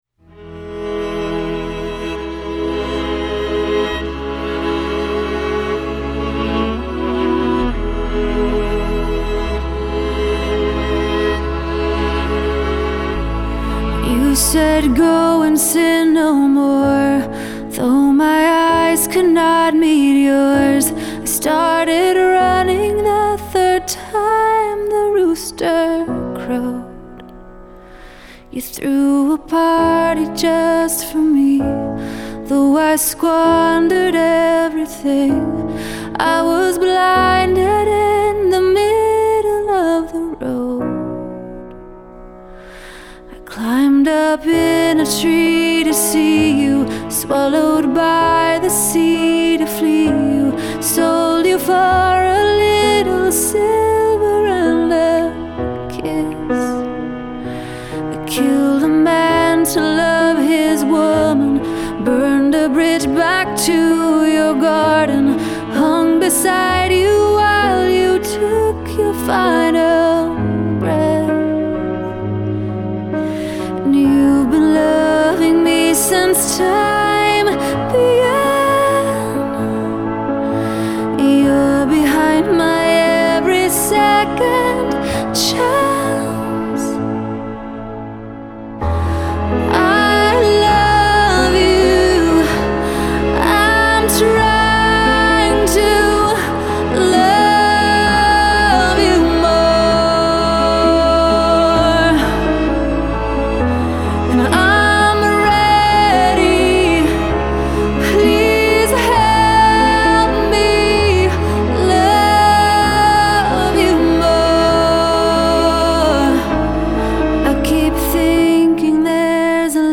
Genre: CCM, Pop, Pop Rock